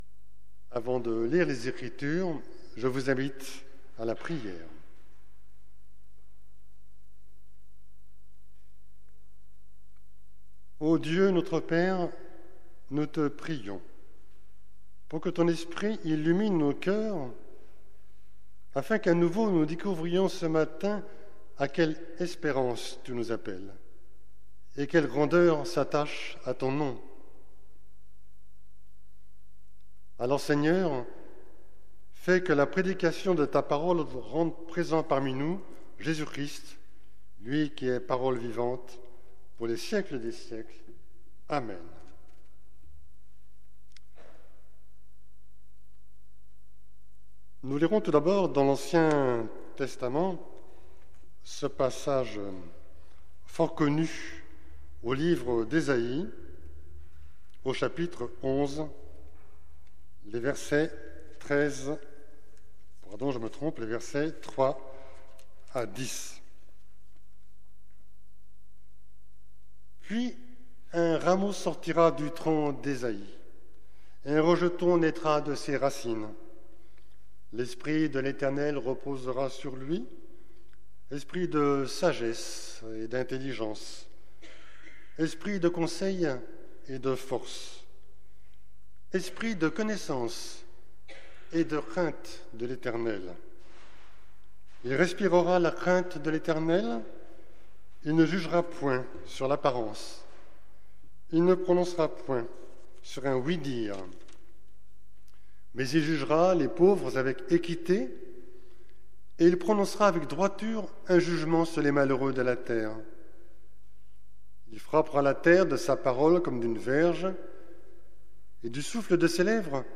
Prédication du 18 décembre 2022